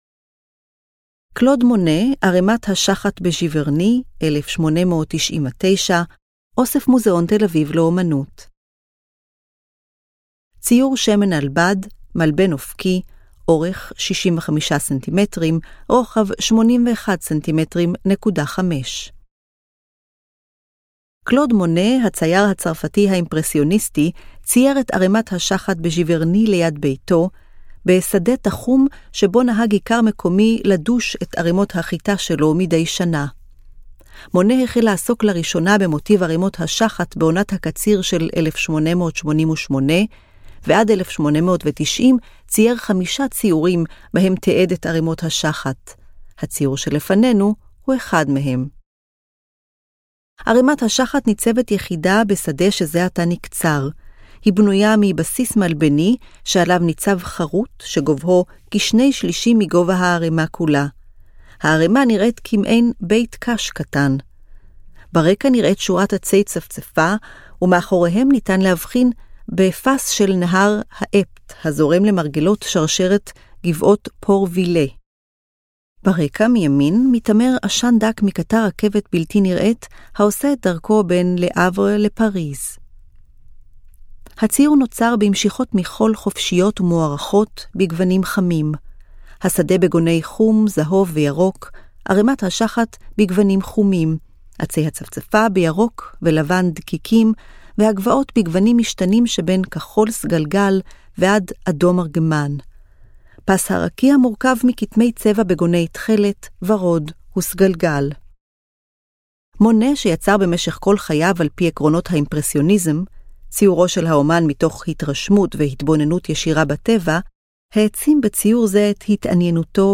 היצירות כולן הונגשו כאמור באמצעות: טקסט – תיאור מורחב המתאר את פרטי היצירה, אודיו – הקלטת התיאור המורחב אותו ניתן לשמוע במדריך הקולי של המוזיאון, גרפיקה טקטילית - הבלטה של היצירות באמצעות מדפסות ברייל, ברייל – תרגום הטקסט המורחב לכתב ברייל.